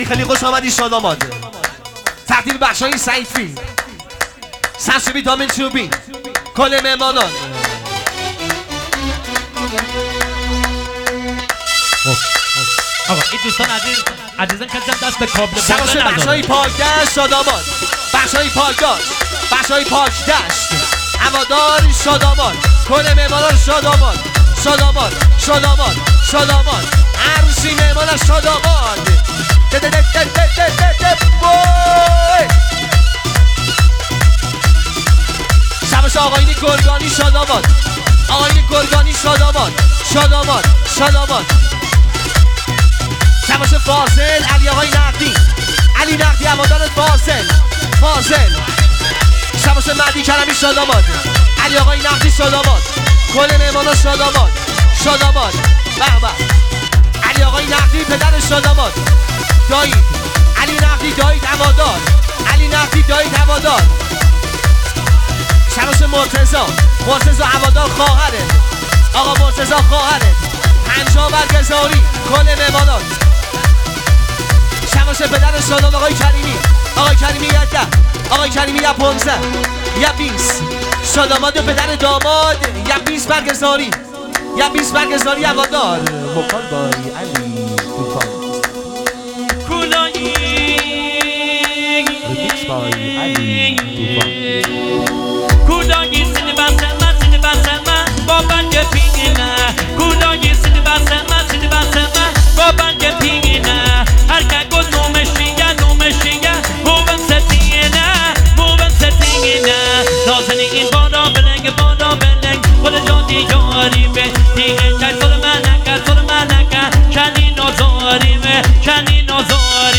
ترانه محلی لری